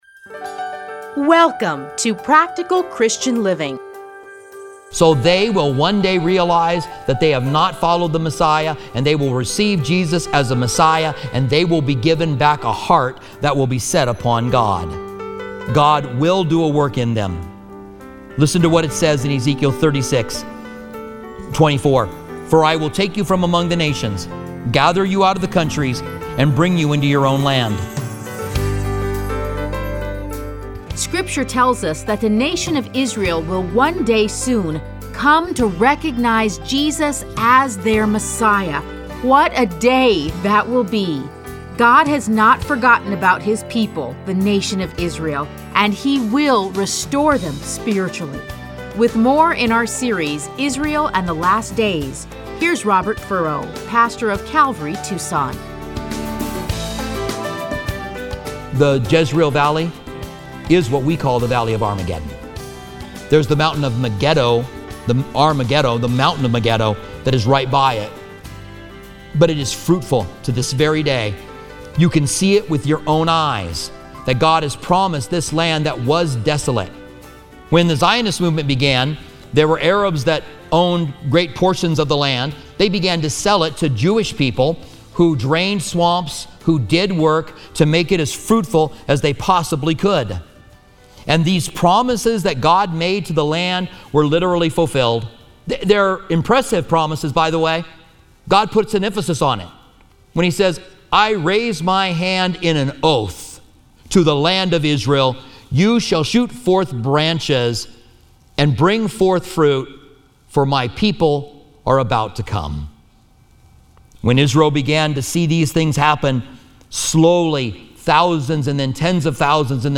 Listen to a teaching from Ezekiel 36-39.